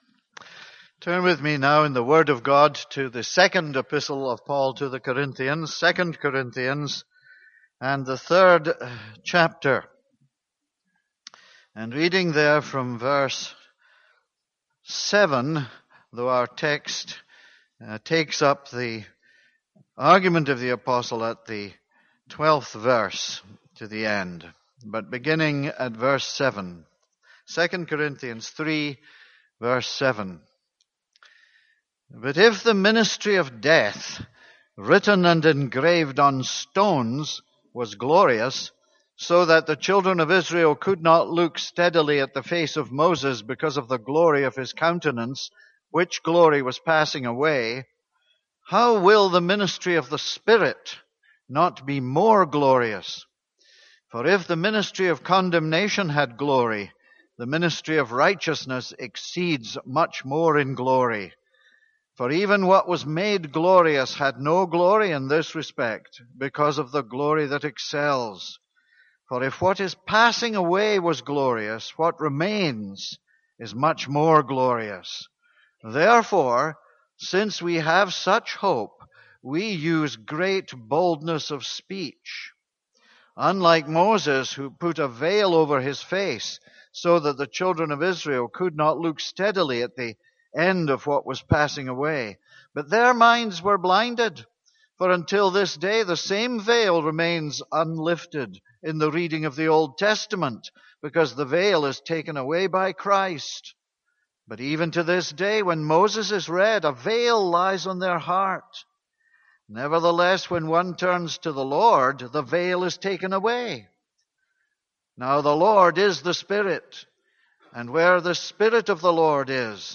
This is a sermon on 2 Corinthians 3:12-18.